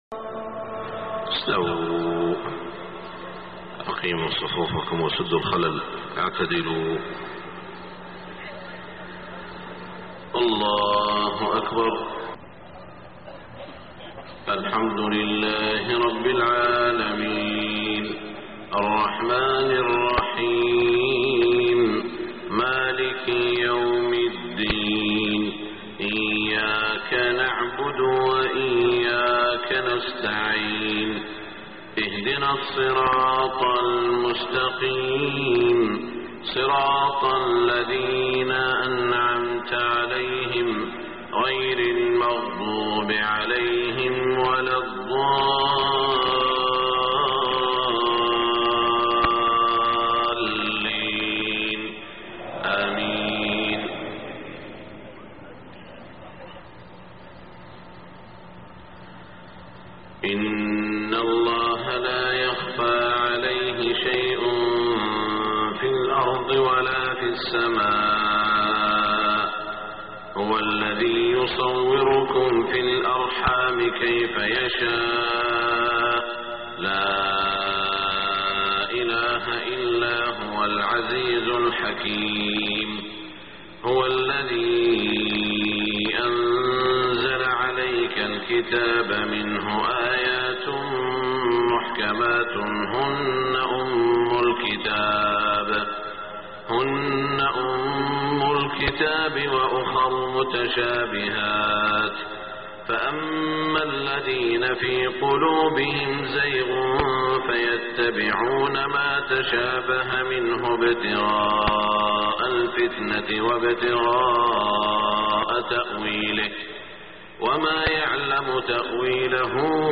صلاة الفجر 9 شوال 1427هـ من سورة ال عمران > 1427 🕋 > الفروض - تلاوات الحرمين